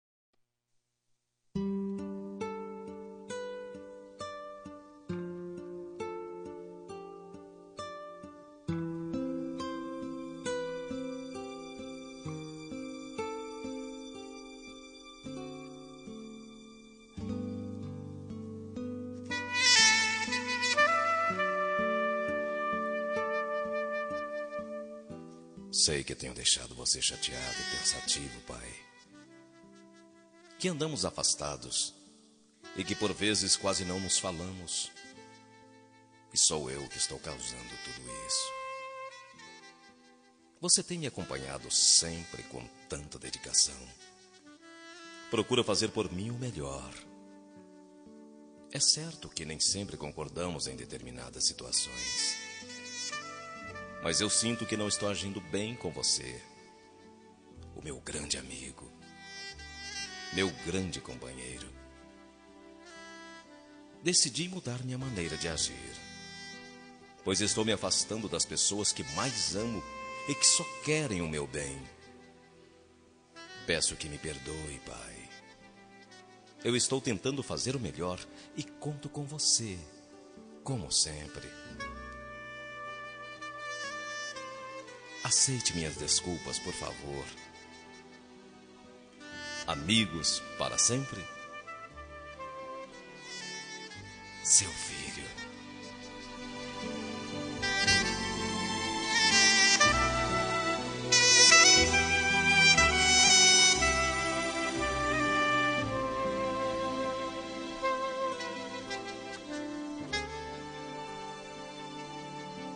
Reconciliação Familiar – Voz Masculina – Cód: 088737 – Pai